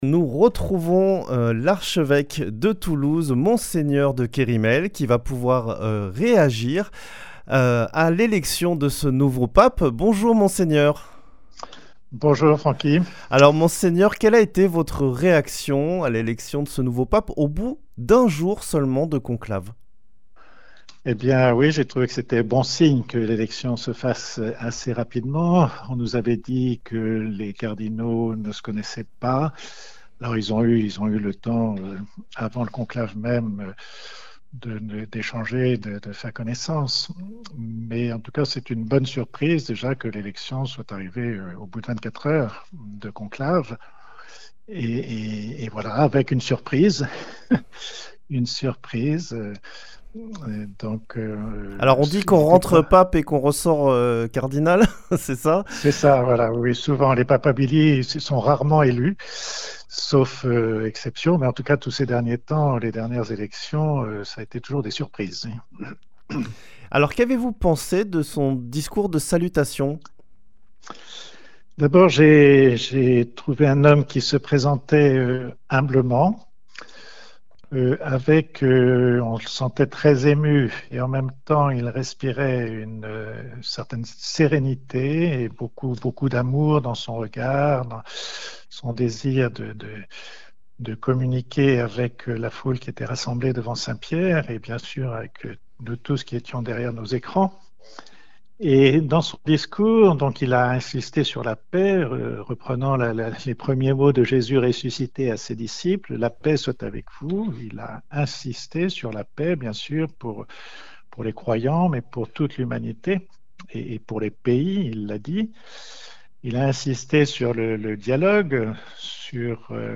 vendredi 9 mai 2025 Le grand entretien Durée 10 min
Un conclave court, une grande émotion, et déjà un discours qui trace des lignes fortes : ce vendredi 9 mai, Mgr Guy de Kerimel, archevêque de Toulouse, revient au micro de Radio Présence sur l’élection du nouveau pape Léon XIV, élu ce jeudi 8 mai 2025.